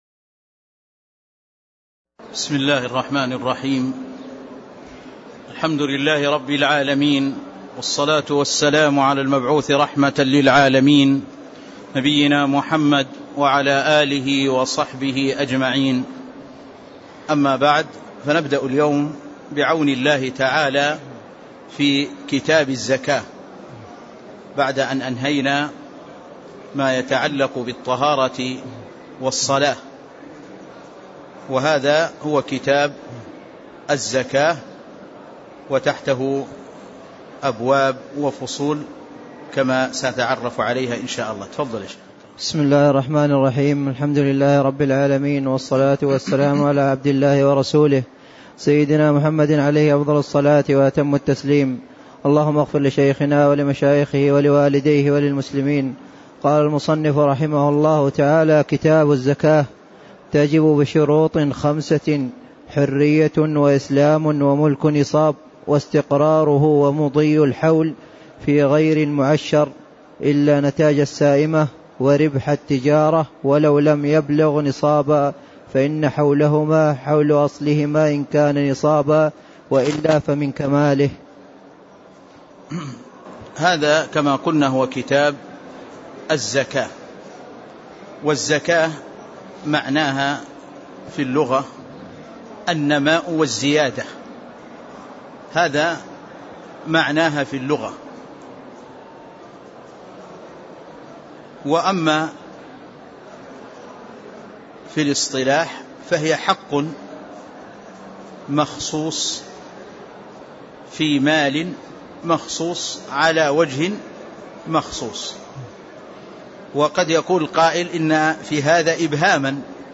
تاريخ النشر ١٢ ربيع الثاني ١٤٣٦ هـ المكان: المسجد النبوي الشيخ